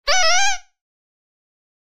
grito11.wav